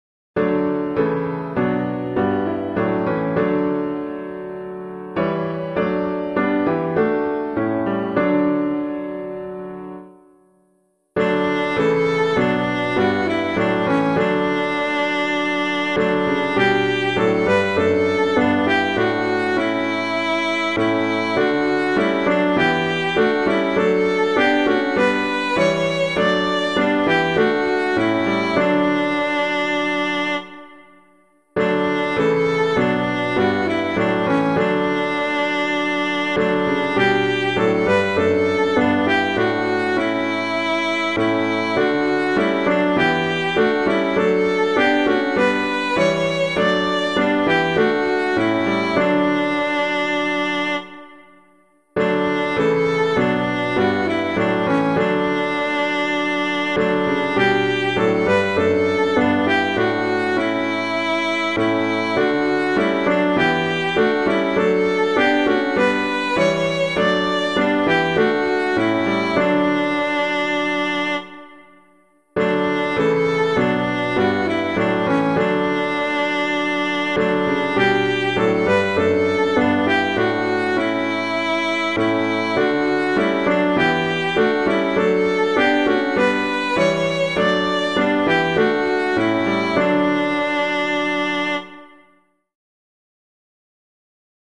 piano
O Bless the Lord My Soul [Montgomery - CARLISLE] - piano.mp3